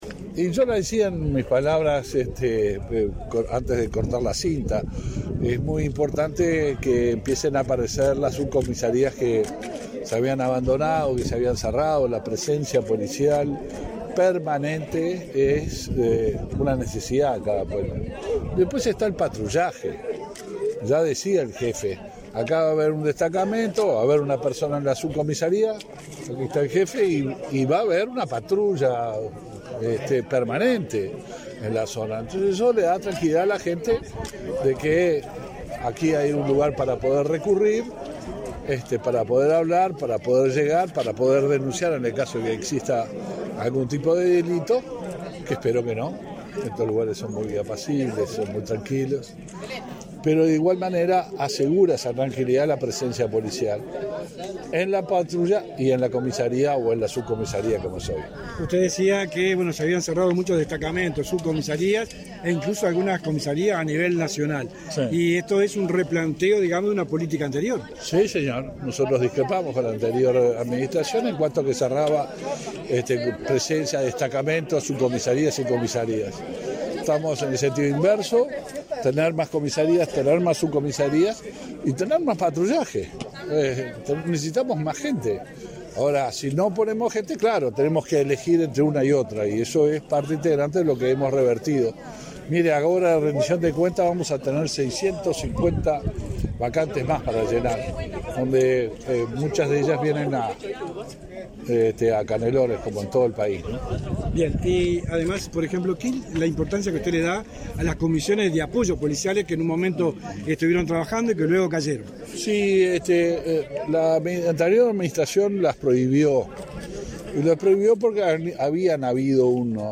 Declaraciones a la prensa del ministro del Interior, Luis Alberto Heber